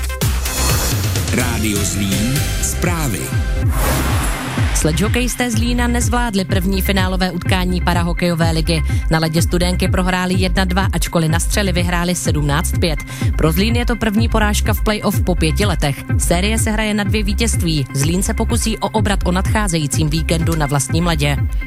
Reportáž po 1. finálovém utkání ČPHL 2018/2019